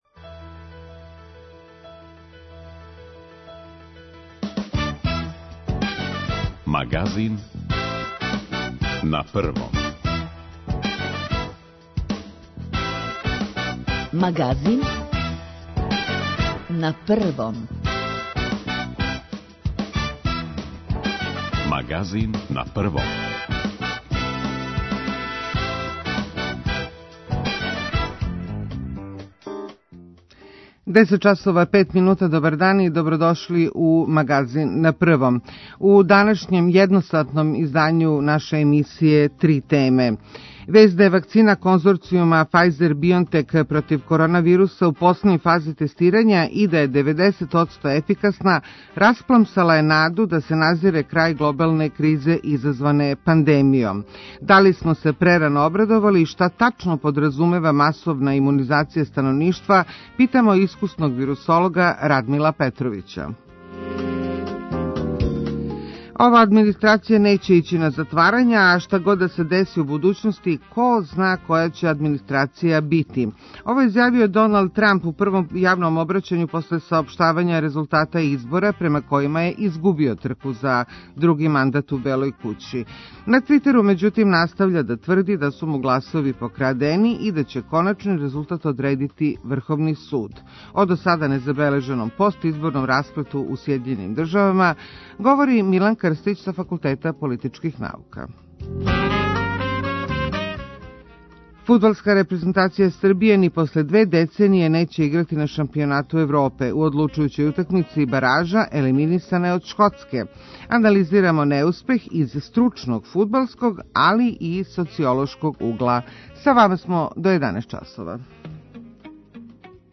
[ детаљније ] Све епизоде серијала Аудио подкаст Радио Београд 1 Београд на ногама Звук монголских степа у 21. веку Софија Соја Јовановић Диана Будисављевић Весна Шоуц